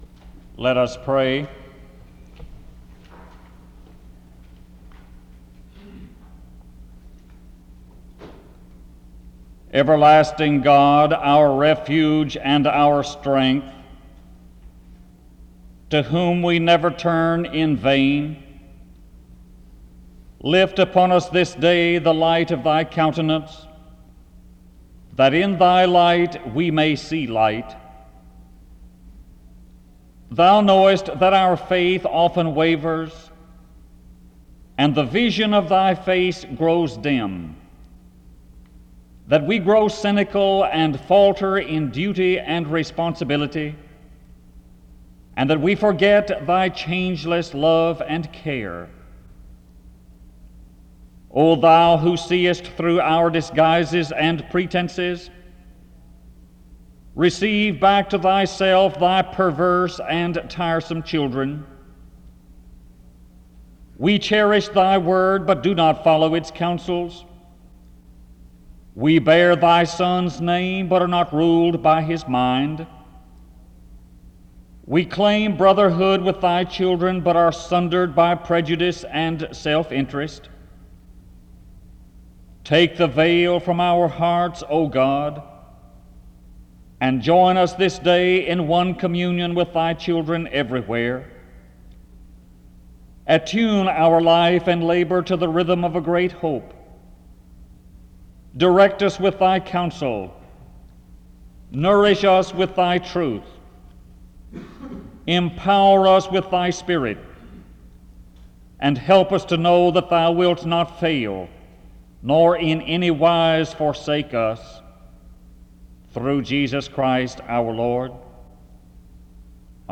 SEBTS Chapel and Special Event Recordings - 1970s